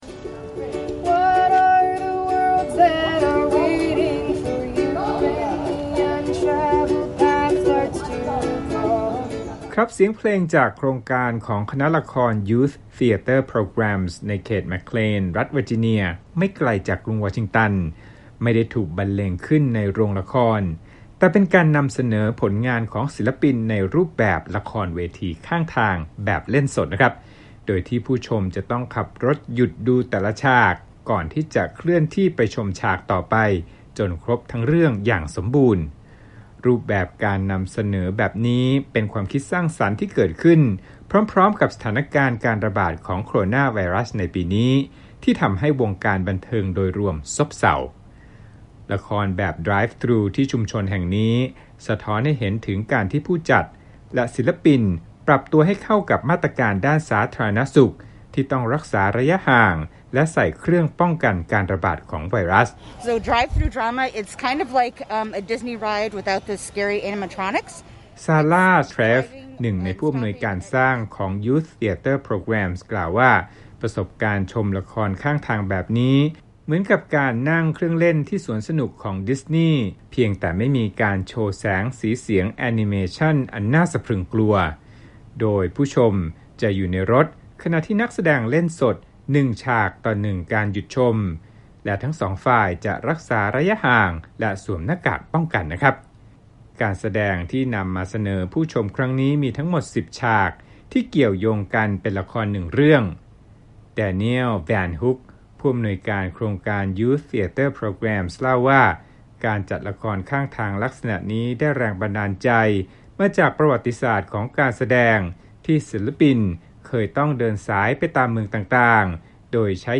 เสียงเพลงจากโครงการของคณะละคร Youth Theater Programs ในเขตเเม็คเคลน รัฐเวอร์จิเนียไม่ไกลจากกรุงวอชิงตัน ไม่ได้ถูกบรรเลงขึ้นในโรงละคร แต่เป็นการนำเสนอผลของของศิลปินในรูปแบบ “ละครเวที ข้างทาง” แบบเล่นสด โดยที่ผู้ชมจะต้องขับรถหยุดดูแต่ละฉาก ก่อนที่จะเคลื่อนรถไปชมฉากต่อไป จนครบทั้งเรื่องอย่างสมบูรณ์